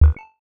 Simple Cute Alert 25.wav